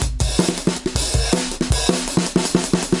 Drumloops " dnb drumloop a 2bars 160bpm
Tag: 低音 沟槽 节奏 碎拍 drumloop drumloop 断线 N 160bpm 节拍 DNB drumgroove